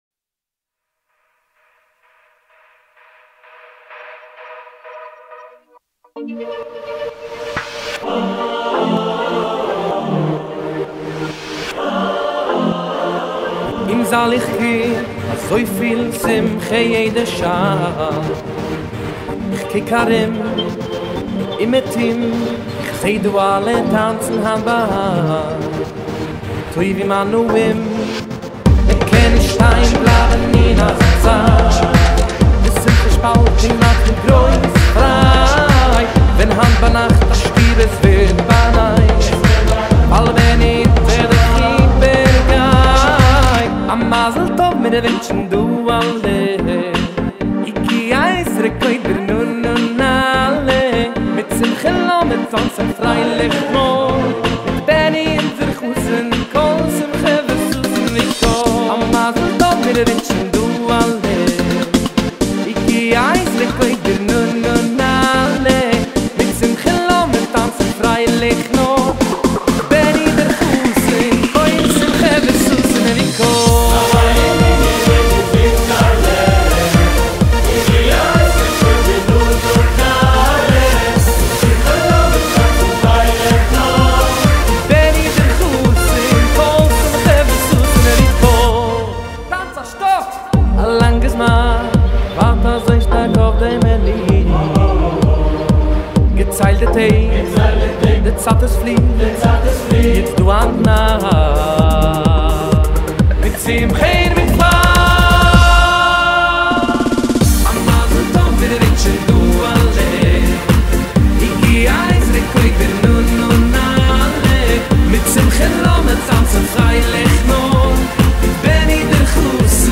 סינגל חדש ומרענן